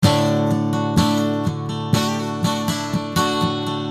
木吉他简单的D和弦Riff简单
Tag: 120 bpm Acoustic Loops Guitar Electric Loops 675.41 KB wav Key : Unknown